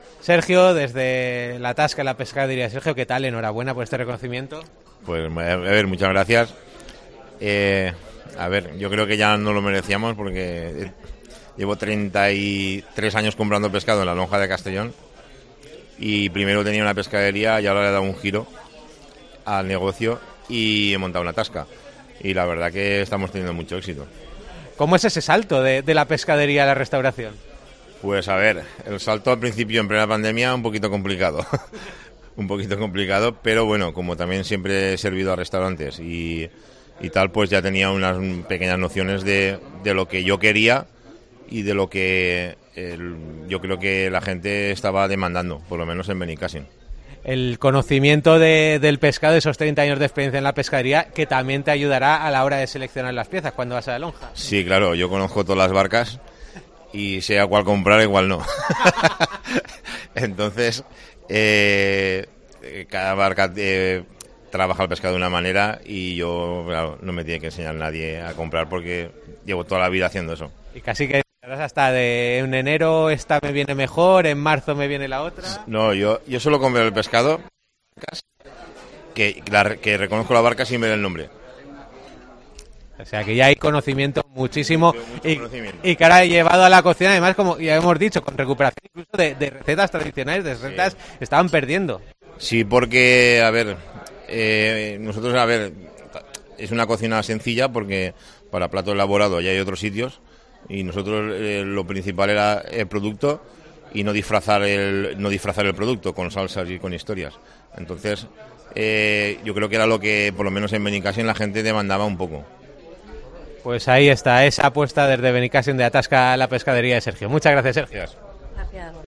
Un total de 10 establecimientos de los que vamos a escuchar sus palabras tras recibir este galardón.